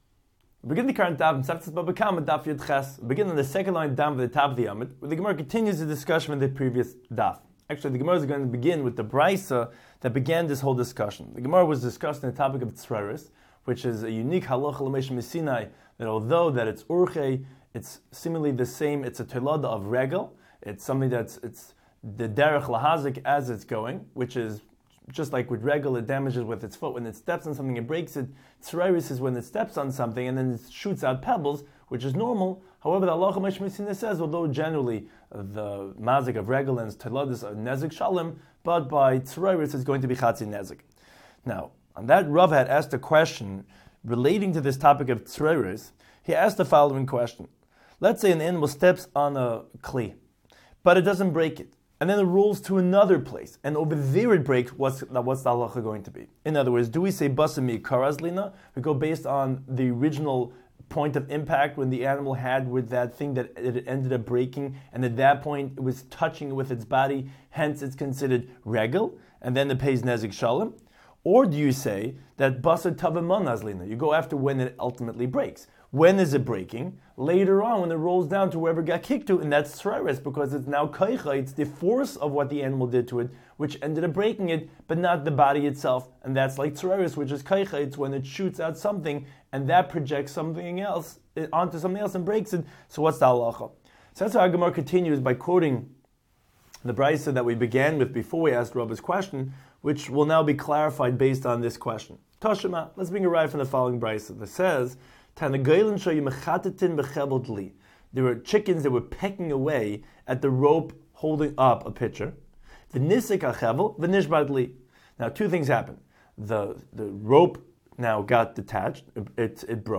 Daf Hachaim Shiur for Bava Kama 18